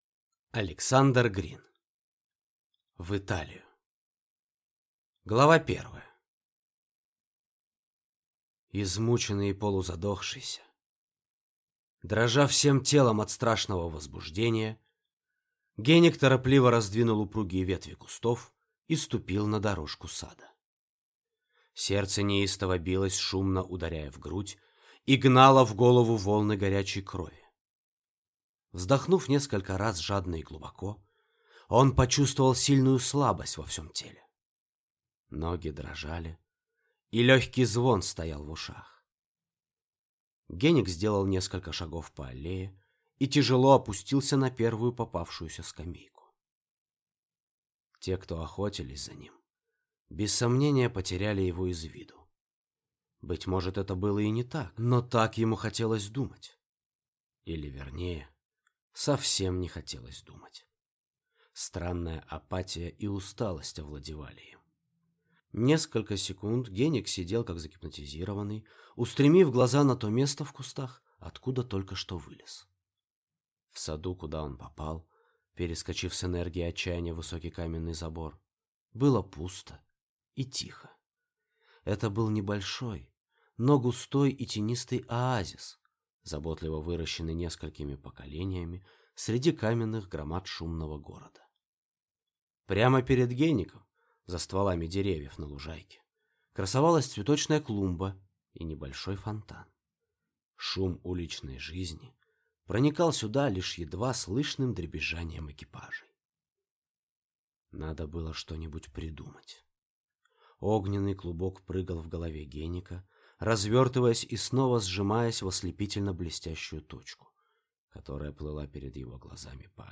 Аудиокнига В Италию | Библиотека аудиокниг